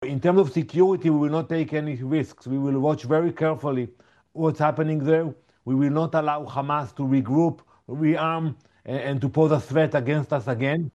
Danny Danon, Israel’s ambassador to the UN, says they’ll closely monitor developments in the enclave: